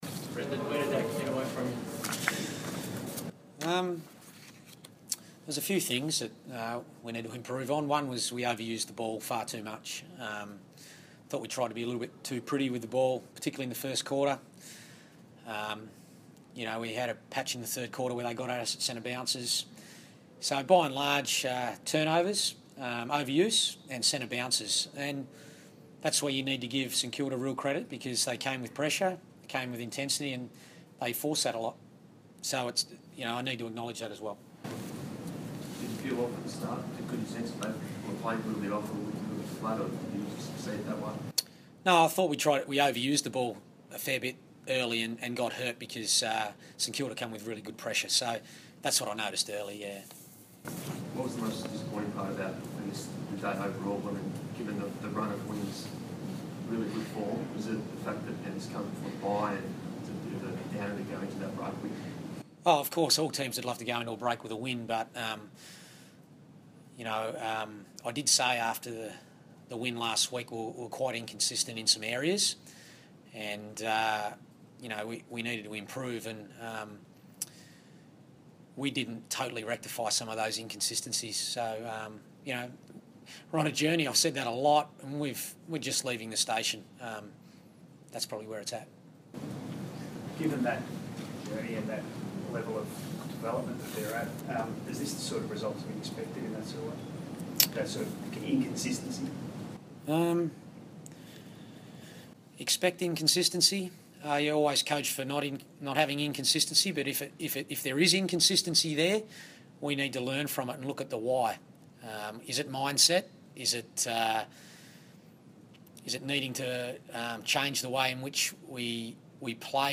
Round 12 post-match press conference